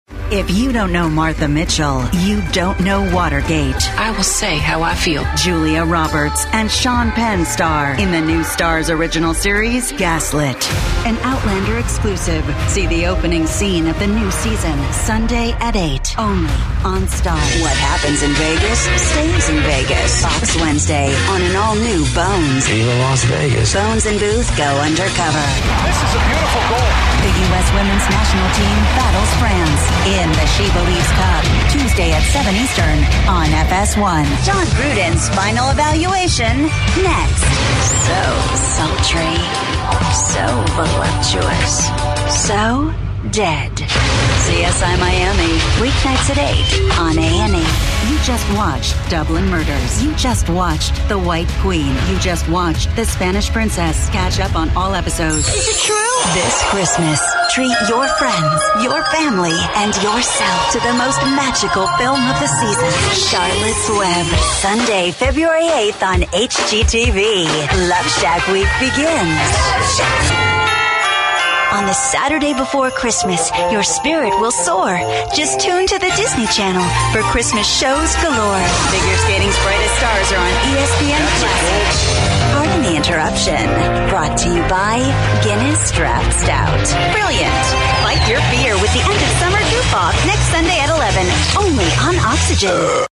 Versatile and seasoned voice.
announcer, antagonistic, anti-announcer, confident, Gravitas, gritty